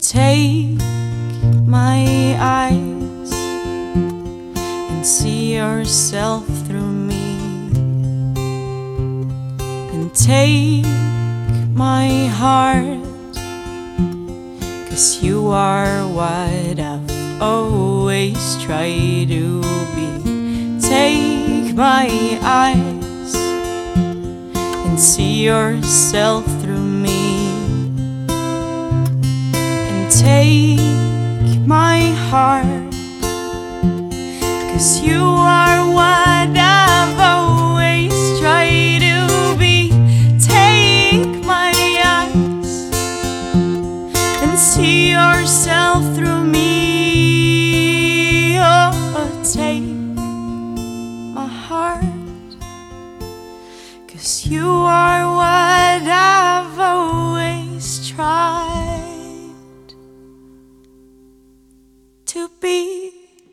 • Elektronisk
• Indie
• Pop
Vokal
Spirende polsk/dansk elektropop duo.